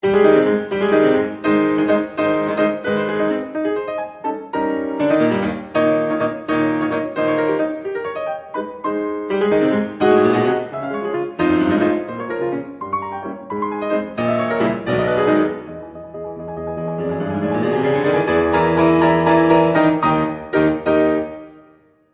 Category: Classical music ringtones